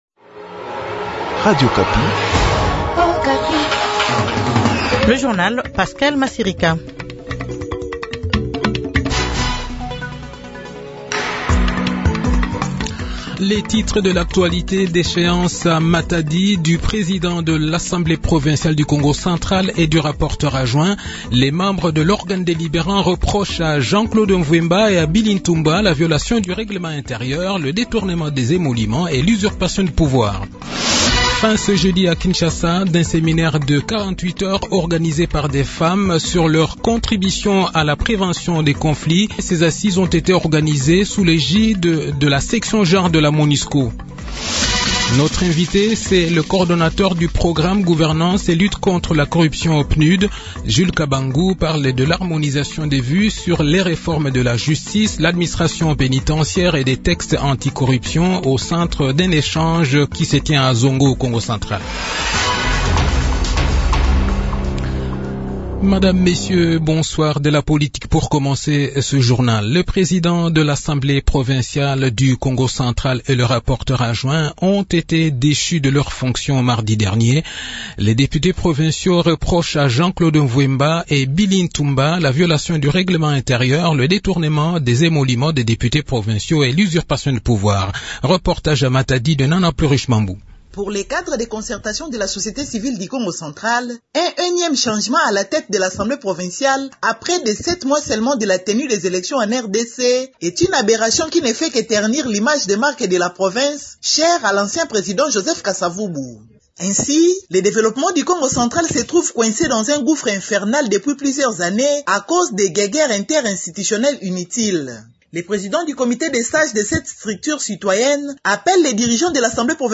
Le journal Soir